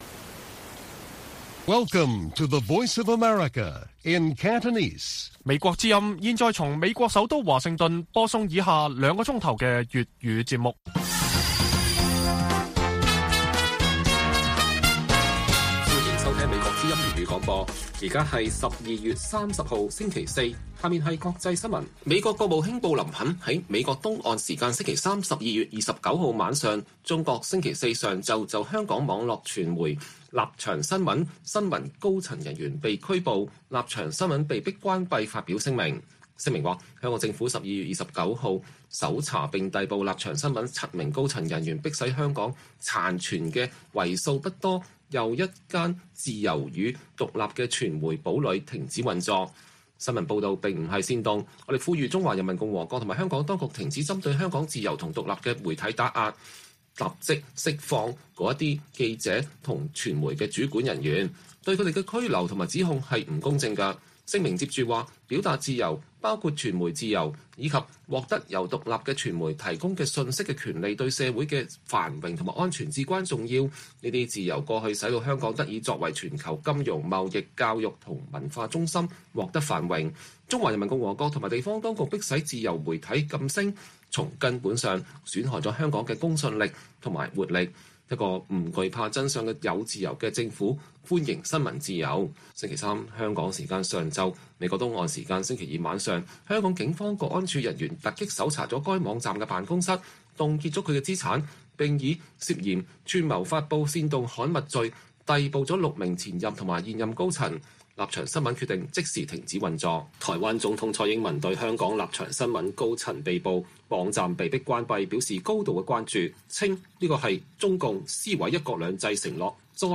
粵語新聞 晚上9-10點: 美國國務卿就香港《立場新聞》高層被捕並被迫關閉發表聲明